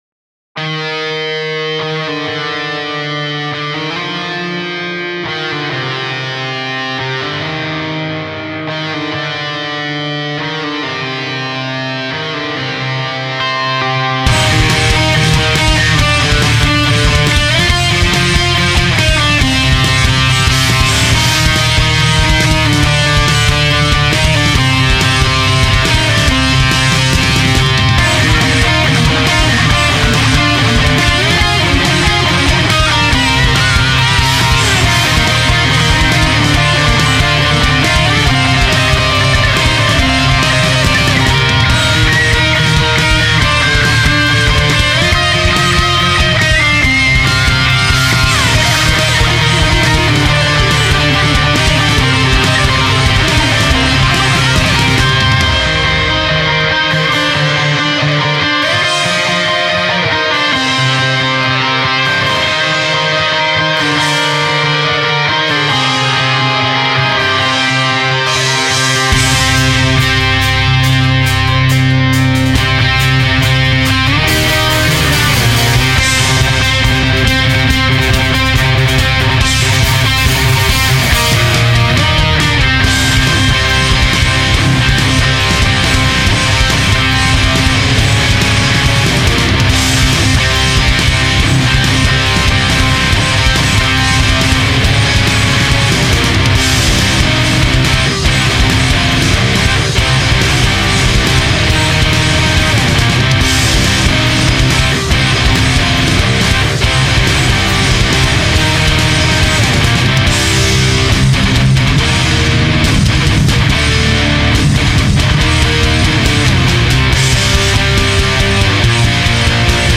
fast heavy metal
sort of anthem-ish